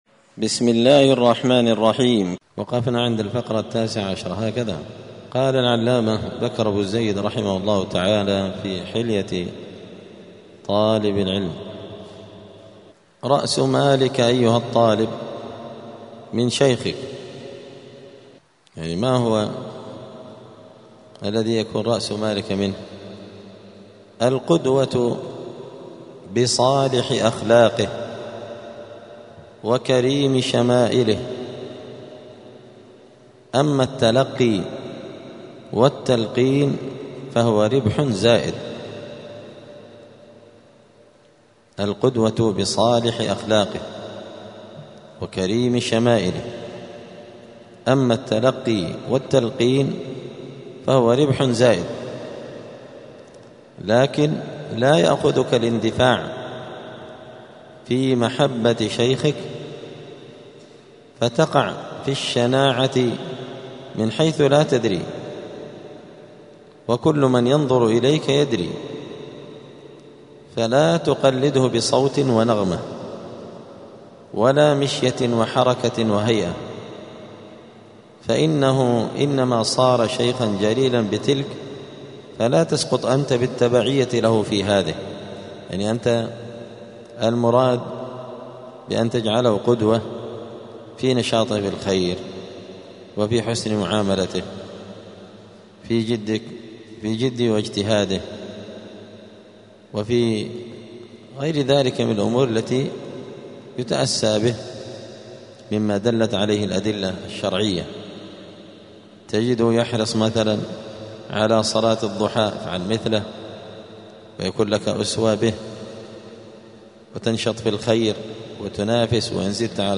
*الدرس الرابع والثلاثون (34) {فصل أدب الطالب مع شيخه رأس مالك أيها الطالب من شيخك}*
الجمعة 21 صفر 1447 هــــ | الدروس، حلية طالب العلم، دروس الآداب | شارك بتعليقك | 27 المشاهدات